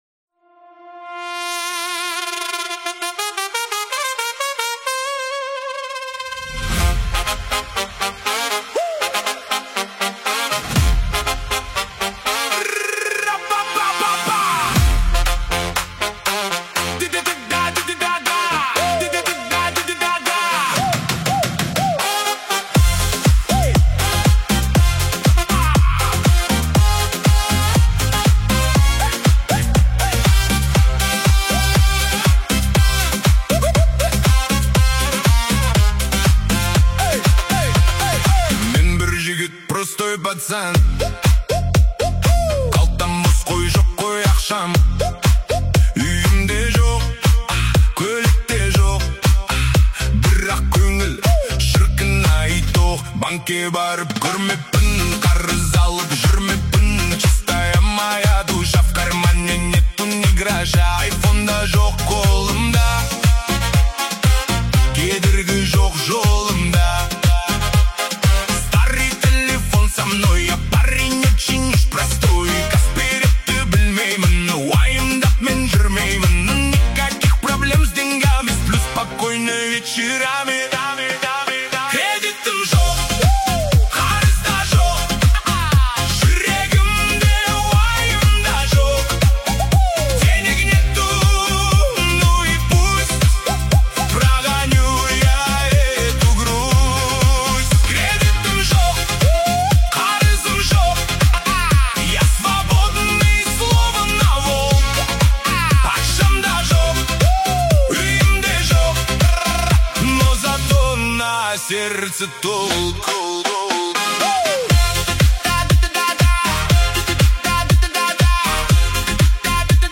жеткізетін көңілді ән